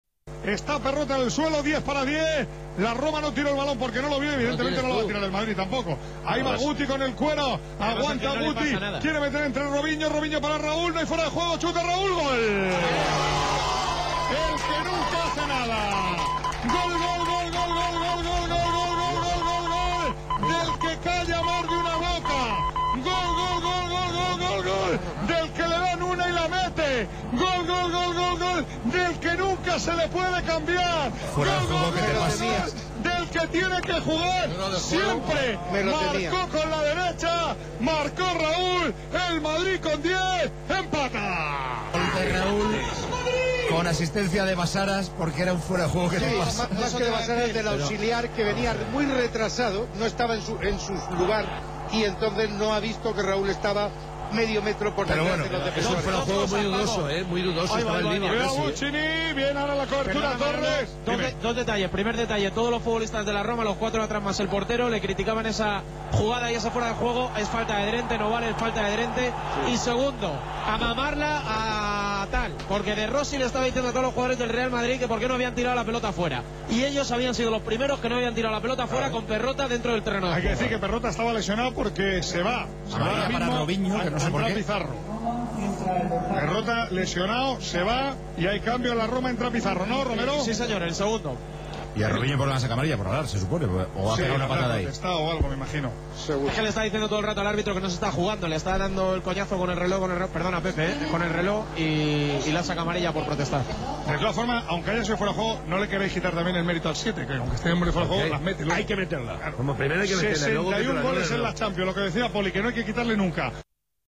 Il commento della radio spagnola ai gol